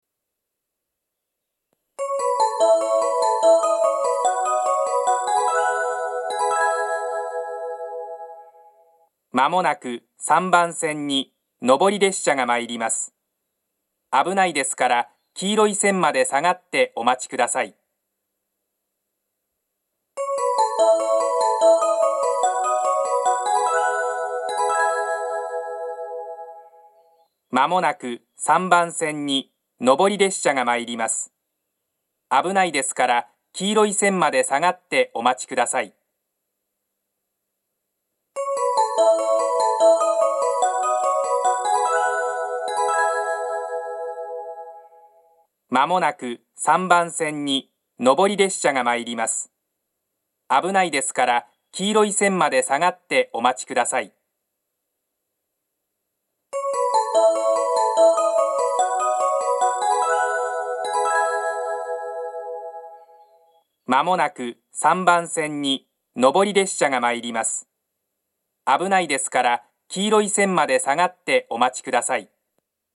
この駅の放送は東北でよく聞ける放送ではなく、カンノの放送です。接近放送は１・２番線は２回、３番線は３回流れます。
３番線接近放送